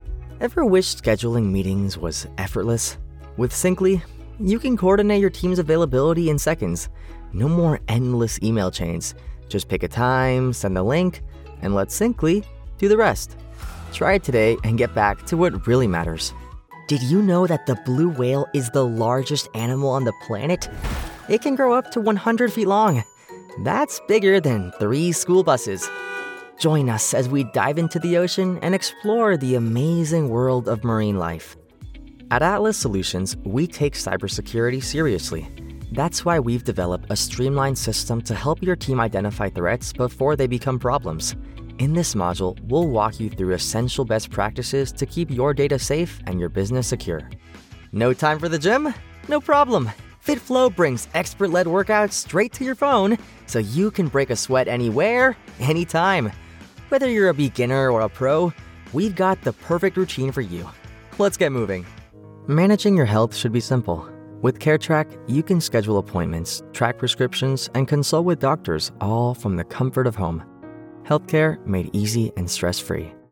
Explainer Sample
Explainer-Sample.mp3